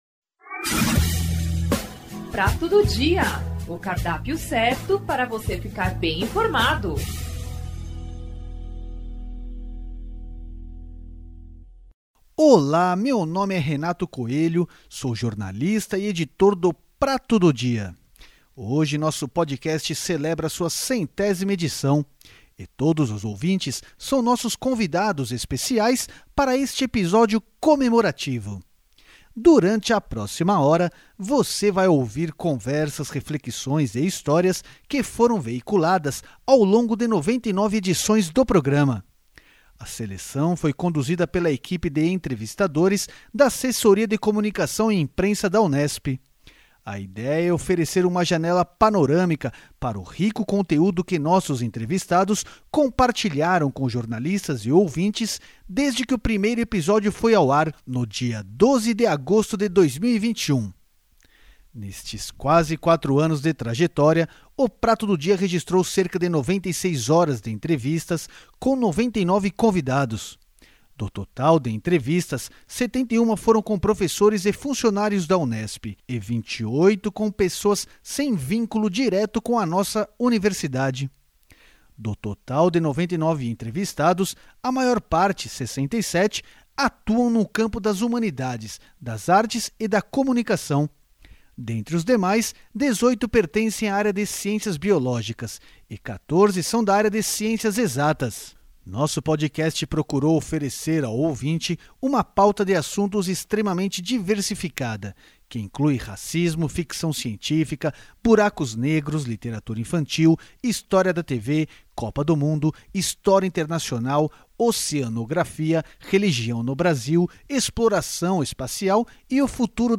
Nesta edição, o ouvinte pode acompanhar conversas, reflexões e histórias que foram veiculadas ao longo de 99 edições do programa.
O “Prato do Dia”, Podcast da Assessoria de Comunicação e Imprensa da Reitoria da Unesp é um bate-papo e uma troca de ideias sobre temas de interesse da sociedade. De maneira informal debateremos tópicos atuais, sempre na perspectiva de termos o contra-ponto, o diferencial.